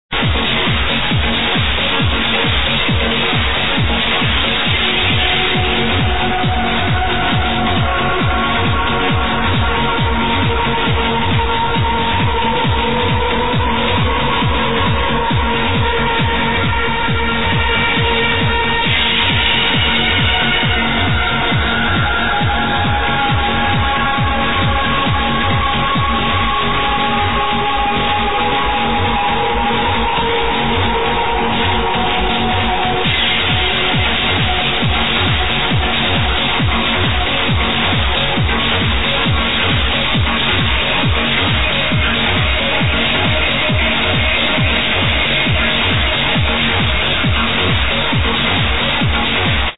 spinning at Ibiza